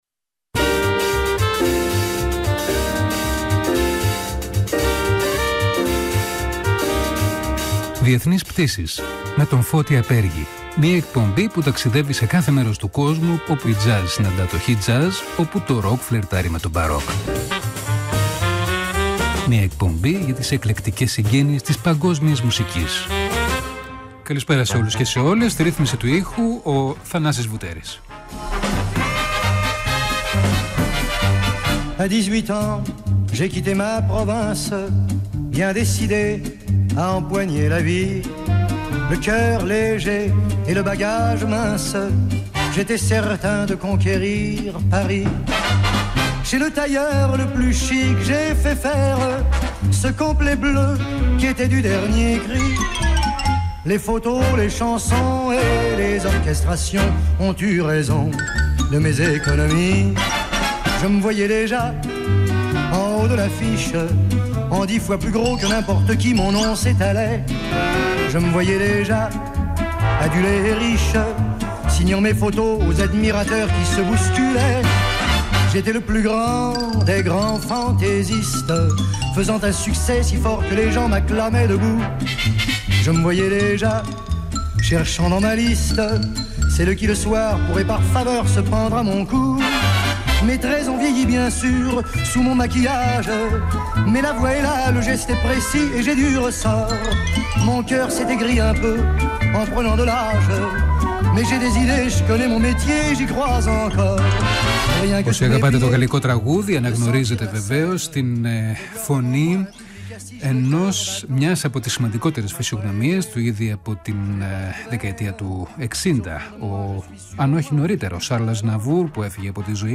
Ακούστε τη συνέντευξη του Charles Aznavour
πριν από μερικά χρόνια στο Παρίσι